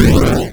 fire_b.wav